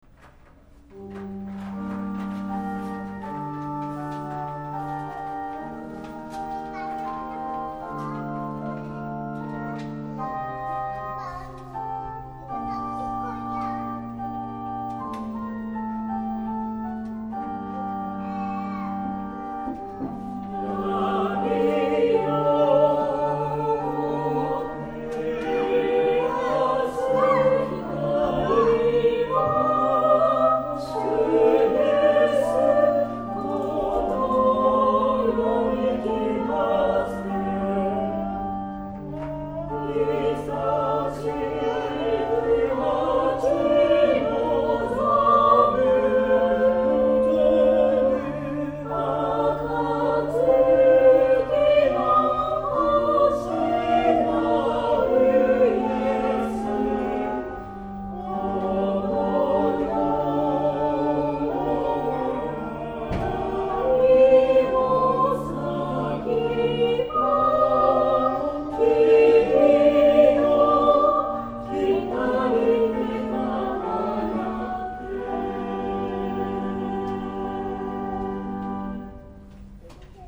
聖歌隊
合唱